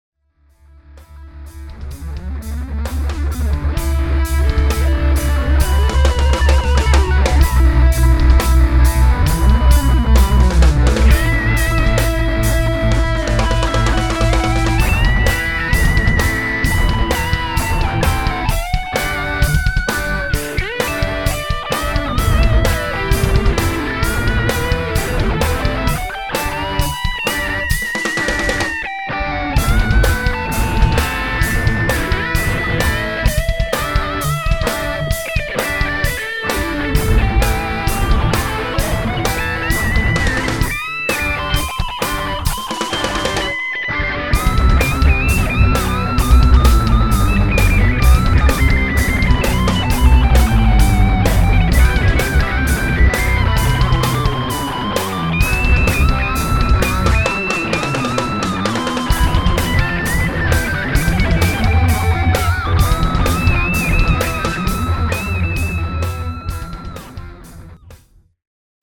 Niagara falls-solo
first take solo,while not perfect,I decided to keep it as it has its moments.